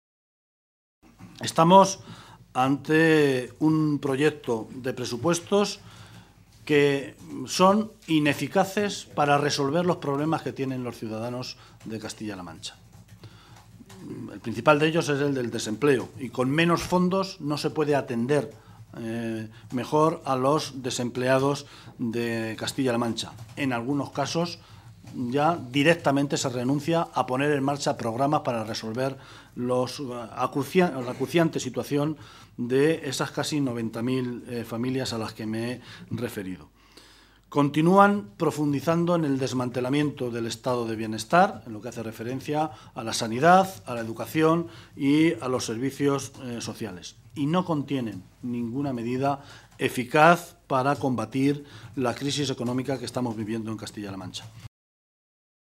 Martínez Guijarro se pronunciaba de esta manera esta mañana, en Toledo, en una comparecencia ante los medios de comunicación en la que aseguraba que “frente a la propaganda de Rajoy y Cospedal”, los de 2014 no eran “los Presupuestos de la recuperación.
Cortes de audio de la rueda de prensa